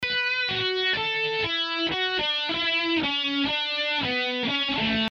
Added an obbligato
Immediately, an obbligato in dotted quarter notes became necessary.